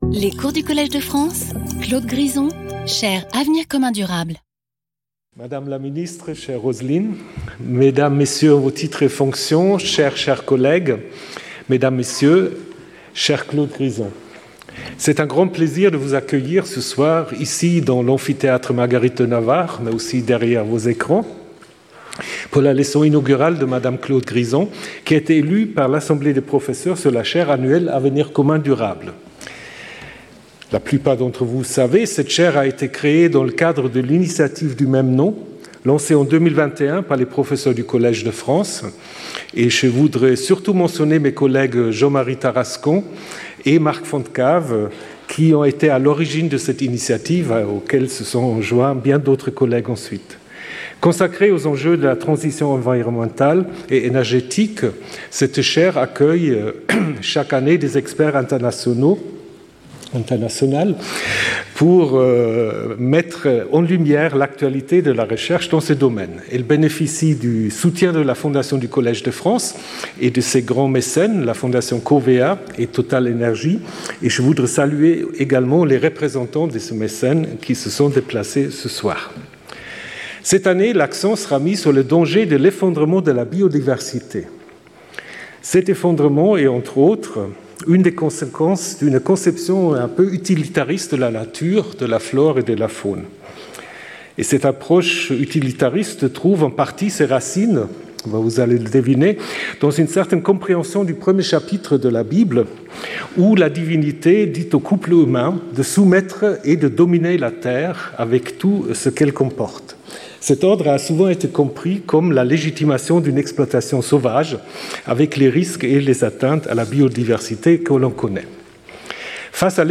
This opening lecture, like the lessons to follow, is a message of hope based on recent scientific research findings.